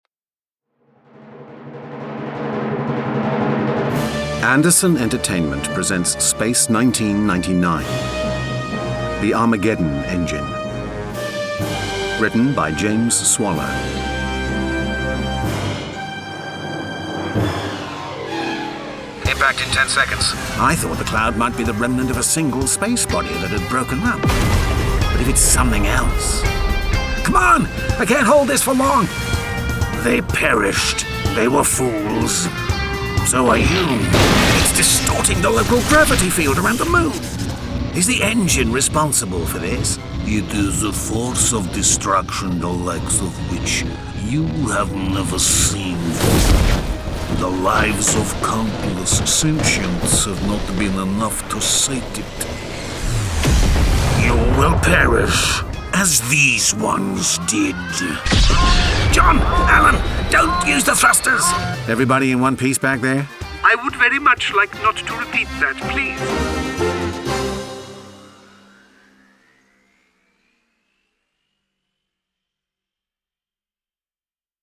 Space 1999: The Armageddon Engine Released September 2024 Written by James Swallow From US $21.36 Download US $21.36 Buy Login to wishlist 3 Listeners recommend this Share Tweet Listen to the trailer Download the trailer